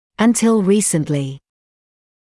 [ənˈtɪl ‘riːsəntlɪ][энˈтил ‘риːсэнтли]до недавнего времени, до недавних пор